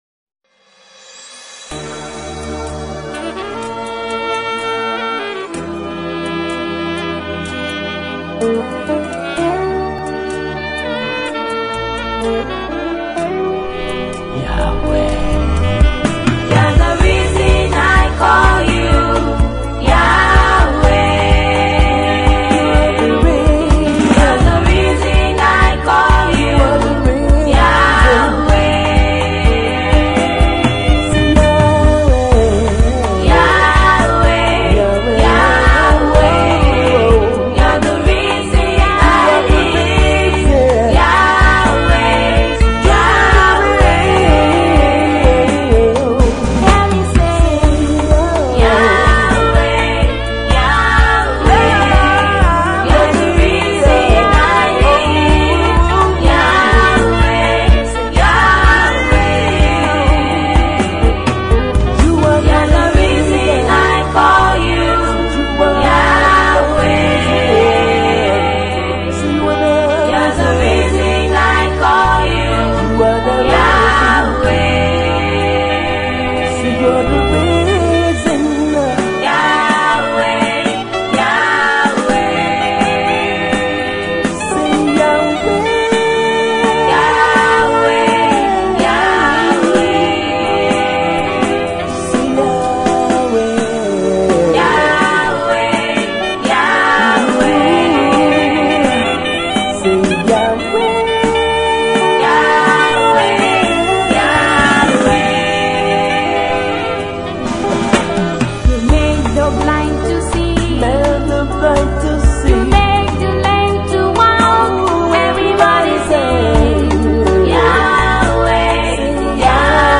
Nigeria Female Gospel minister and songwriter